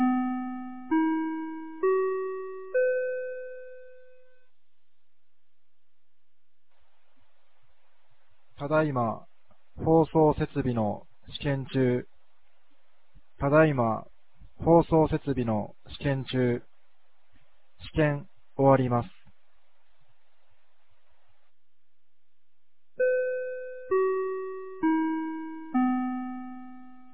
2025年11月22日 16時04分に、由良町から全地区へ放送がありました。